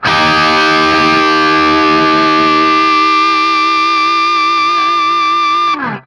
TRIAD D#  -L.wav